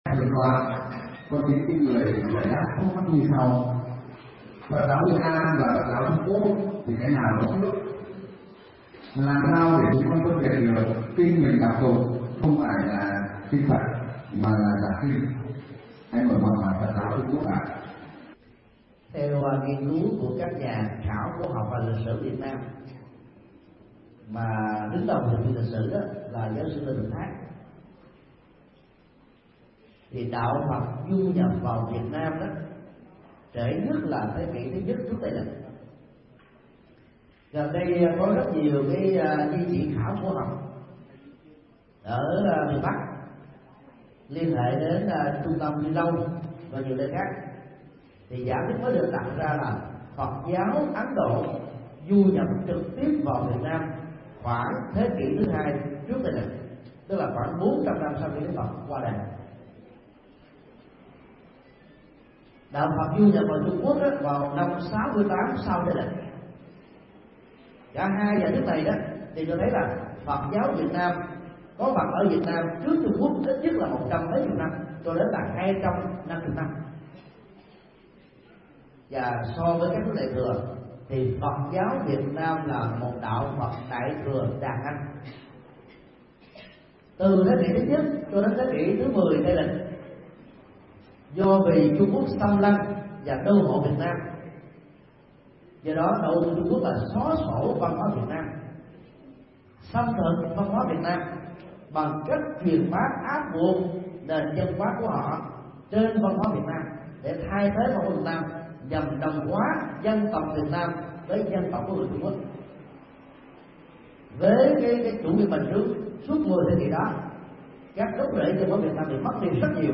Vấn đáp: Phân biệt Phật giáo Việt Nam và Trung Quốc, kinh điển Phật và kinh có xuất xứ từ Trung Quốc